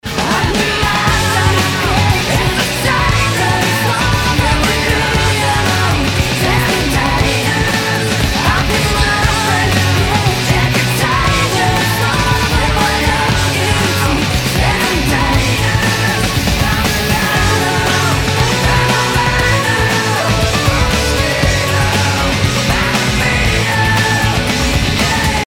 Немного поп музыки )
Ну тут был просто совет еще вокал поднять)) ,куда еще больше ) Ну гипотетически его плохо слышно в куплетах, причём именно в середине.
А "сыкает" тоже смачно.